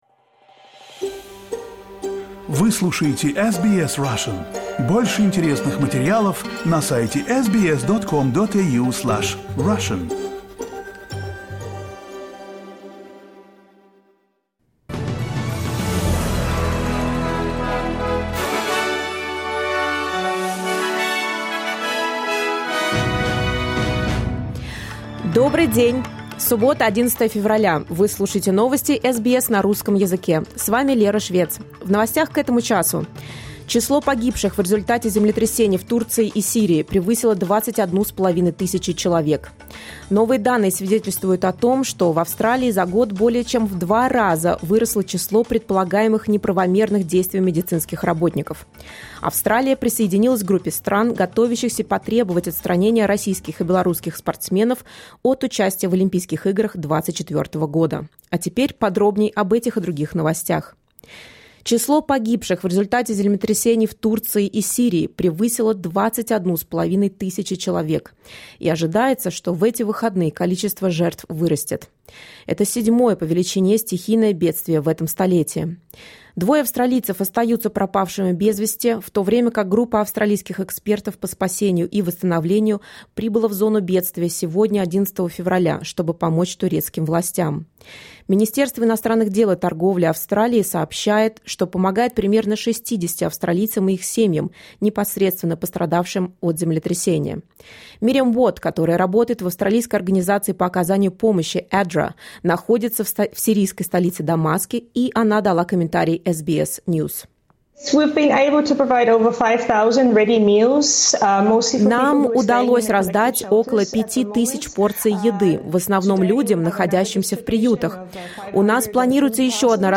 SBS news in Russian — 11.02.2023
Listen to the latest news headlines in Australia from SBS Russian